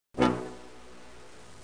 PIZZICAT.mp3